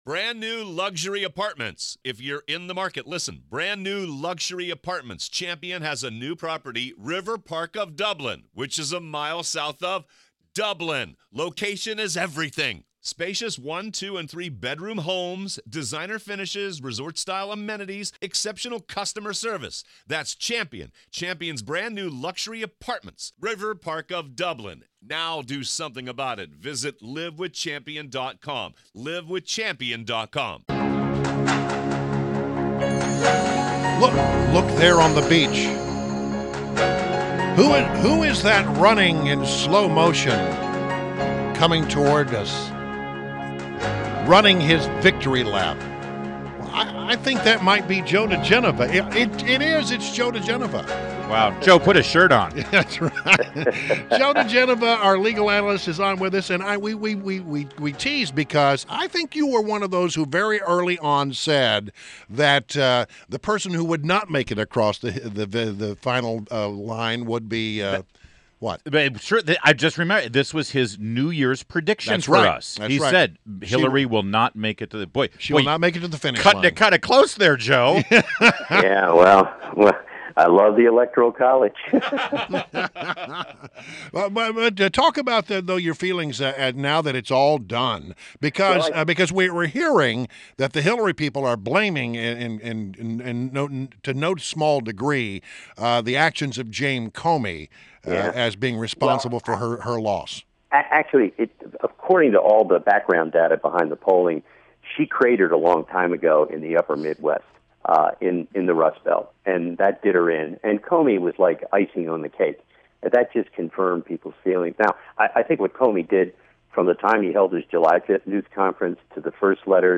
INTERVIEW — JOE DIGENOVA – legal analyst and former U.S. Attorney to the District of Columbia